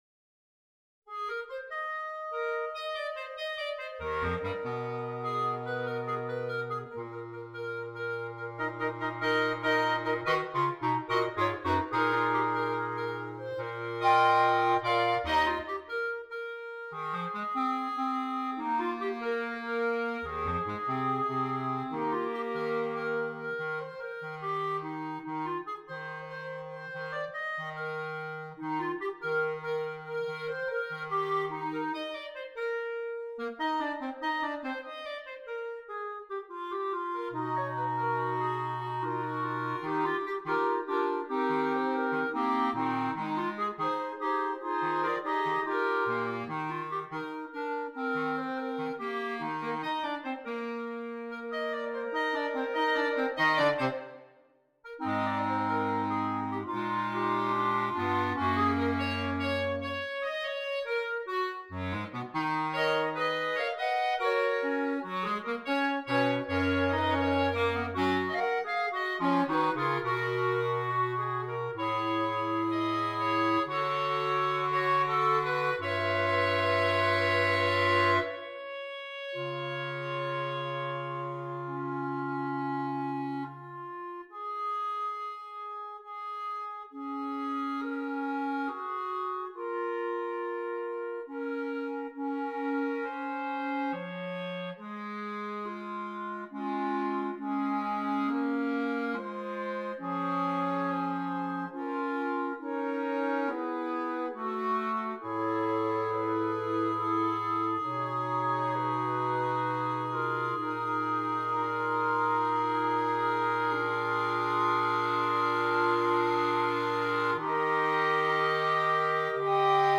2 Clarinets